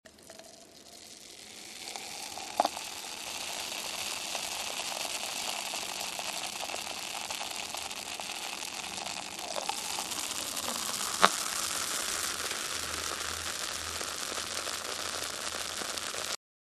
ECOUTER BIERE QUI MOUSSE
Son d'ouverture d'une bouteille de bière Lion.
biere-qui-mousse.mp3